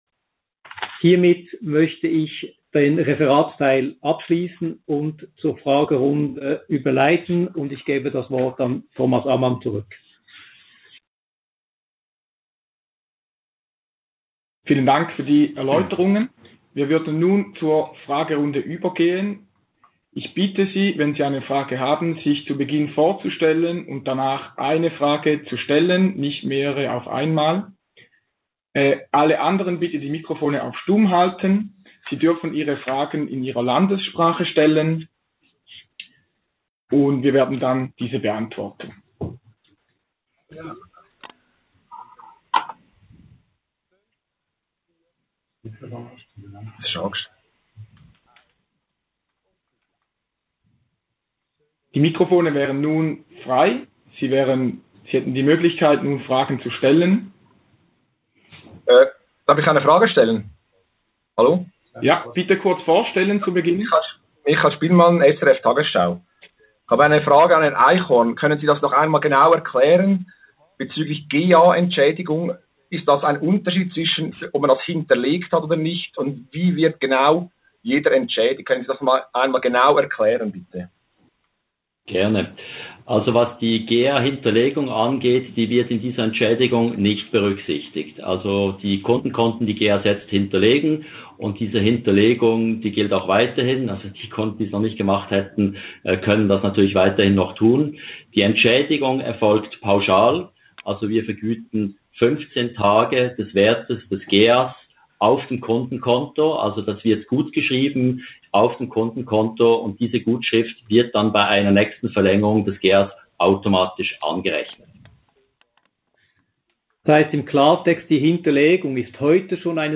conférence de presse du 8 avril 2020